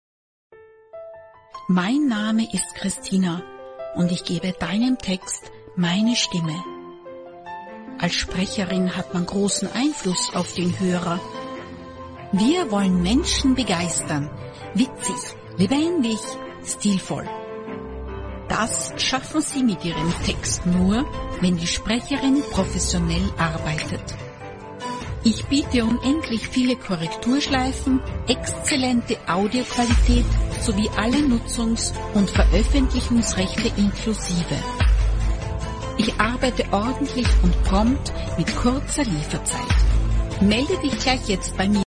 配音风格： 大气 稳重 磁性 成熟 轻松 甜美 亲切 温暖 讲述